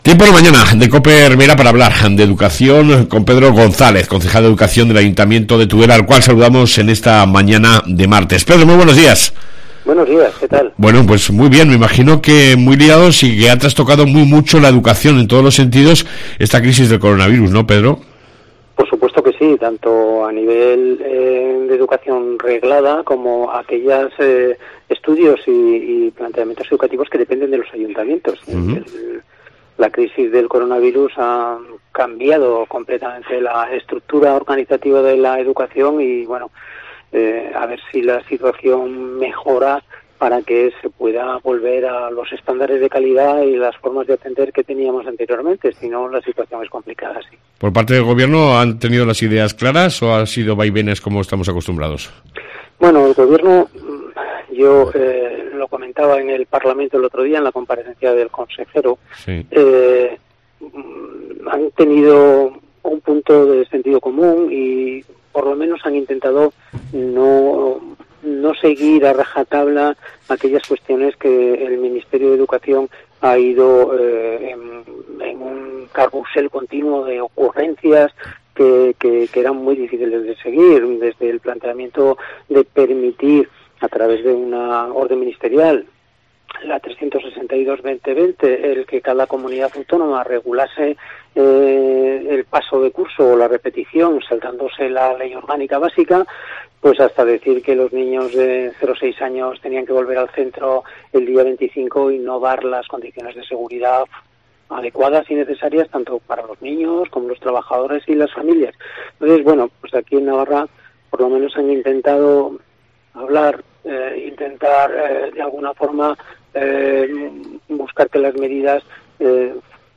AUDIO: Hablamos con el Concejal Pedro González sobre la financiación del Conservatorio de Tudela.